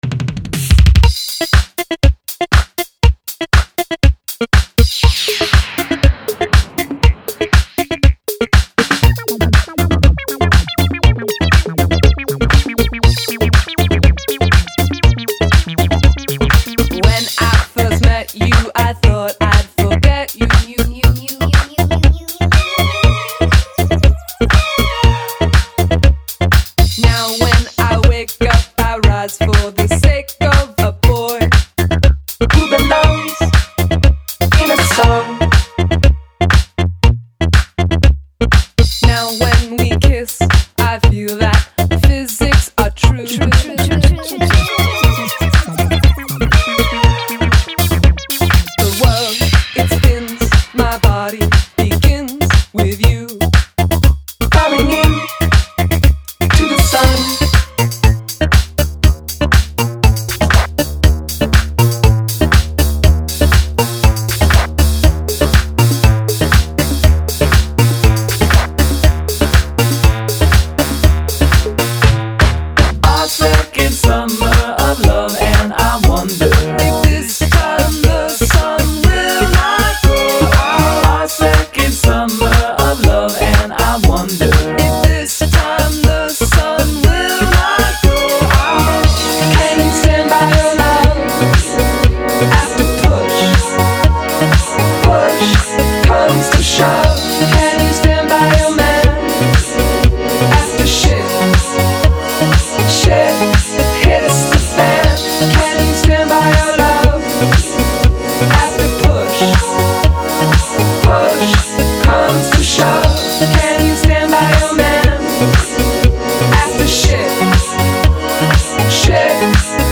Electro/SynthPop